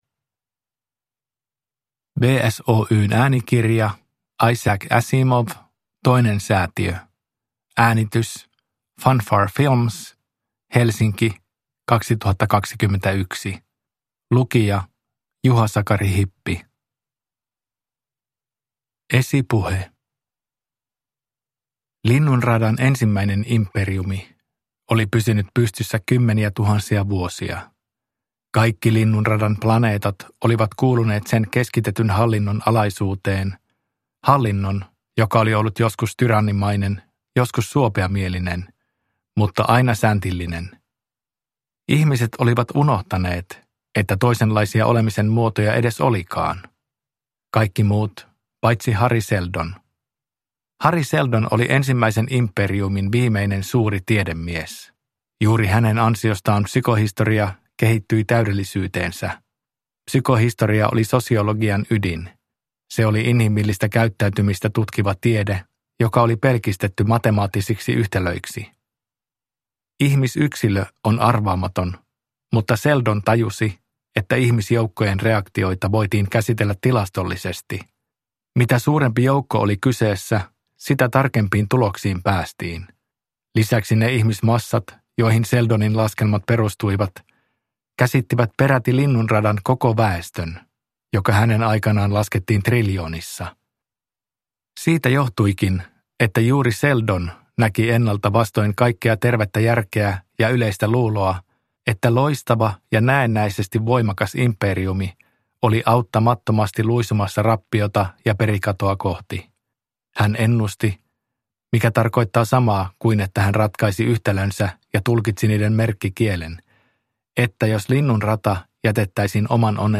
Toinen Säätiö – Ljudbok – Laddas ner
Huomautus kuuntelijoille: äänikirjassa esiintyvät otteet Galaktisesta ensyklopediasta päättyvät vaimennukseen.